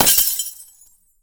ice_spell_impact_shatter_04.wav